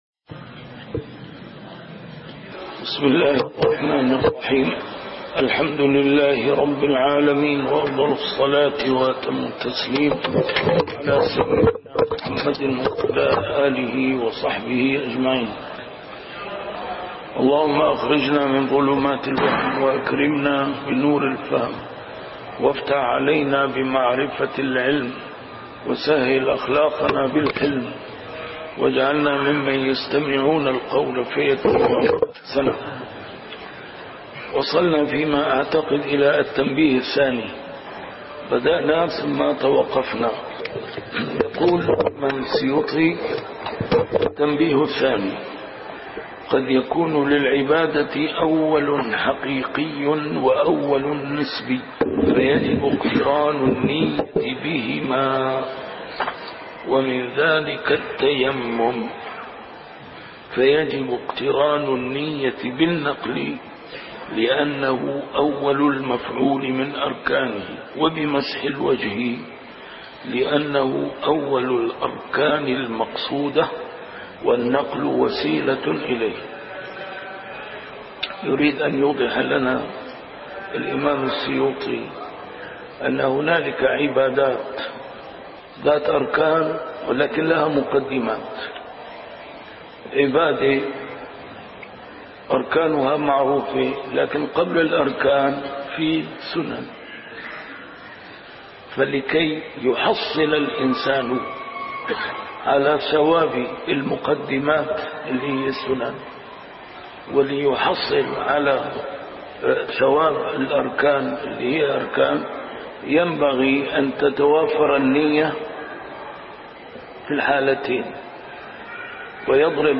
كتاب الأشباه والنظائر، الدرس الثالث عشر: في وقت النية